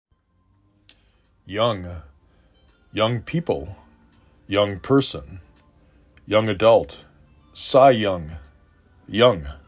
young 3 /y/ /uh/ /N/ Frequency: 855:
y uh N